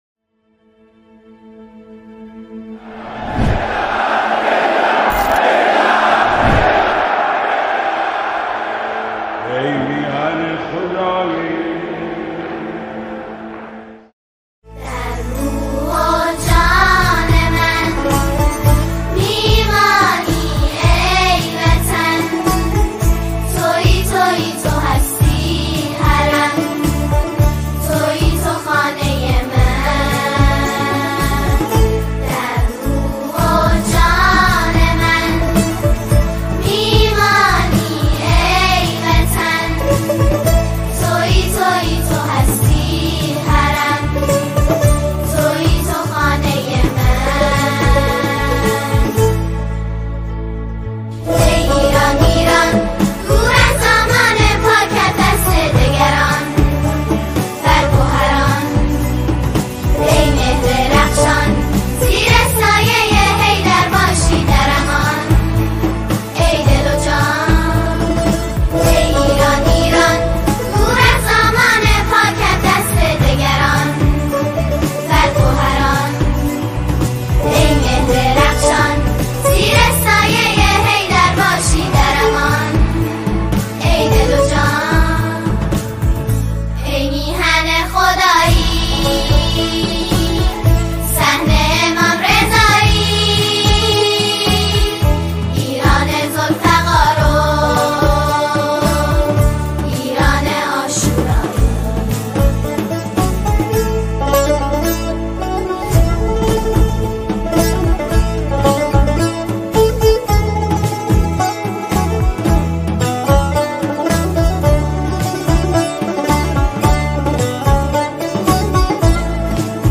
نماهنگ
دختران
اثری است سرشار از عاطفه دینی و شور میهنی
ژانر: سرود